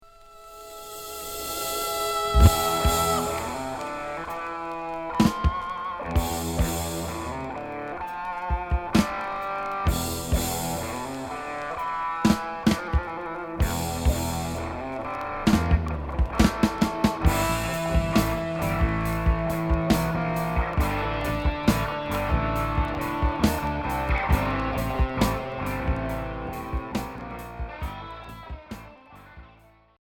Hard progressif Unique 45t